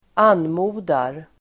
Uttal: [²'an:mo:dar]
anmodar.mp3